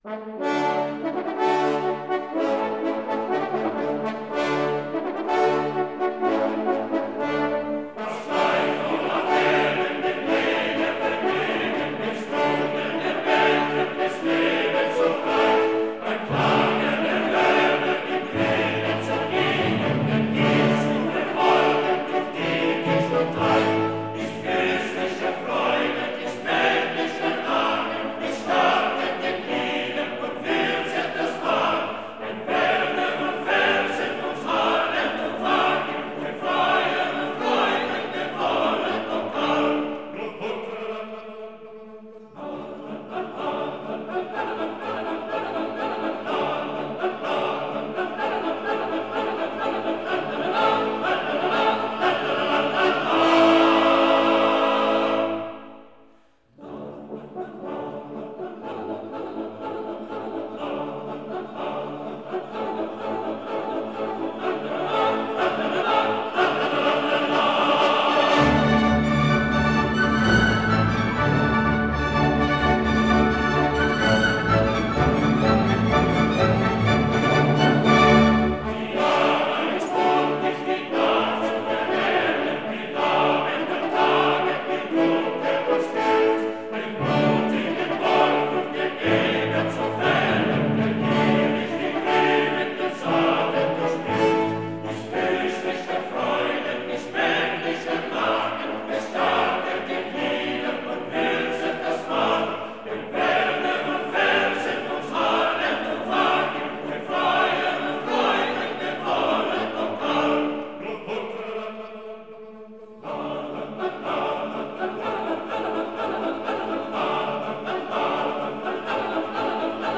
Coro de la ópera 'El Cazador Furtivo'